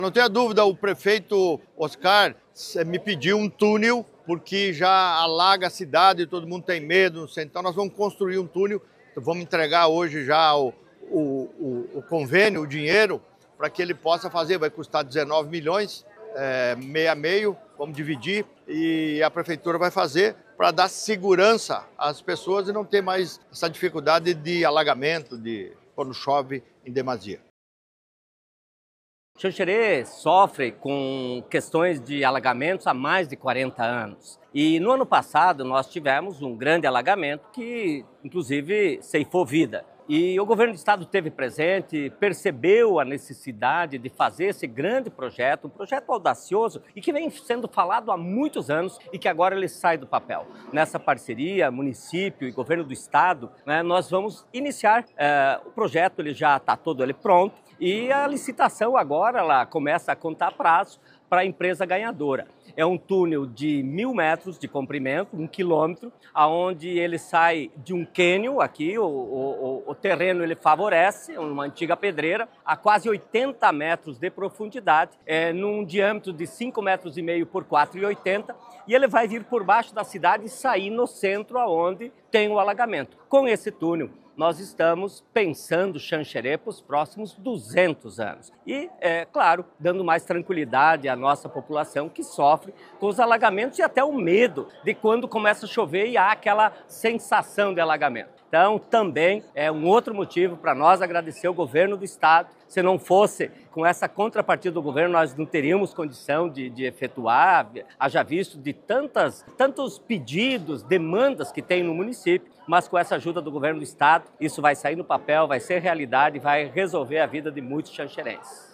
O governador Jorginho Mello fala da importância da obra para a cidade:
De acordo com o prefeito de Xanxerê, Oscar Martarello, a obra é pedida há anos pela comunidade, o projeto já está pronto e a parceria do Estado vai permitir que a intervenção tenha início: